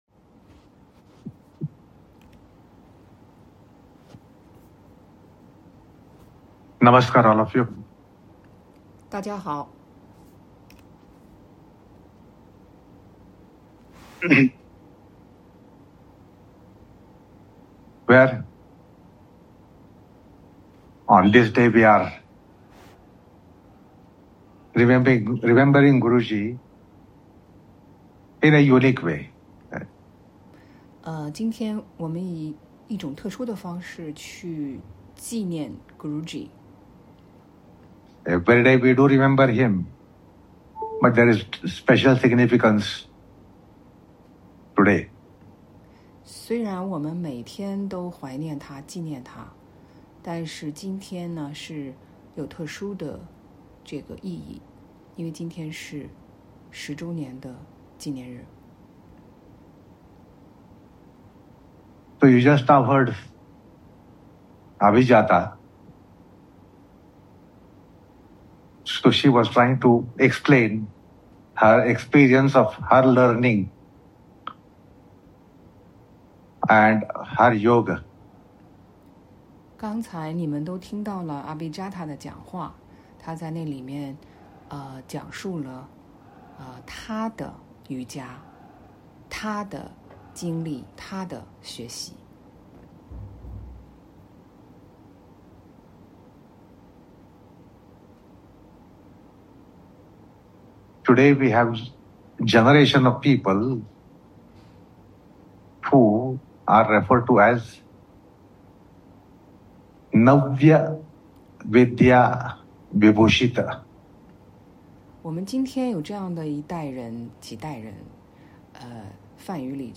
希望中文的翻译音频，可以帮到有需要的瑜伽学生，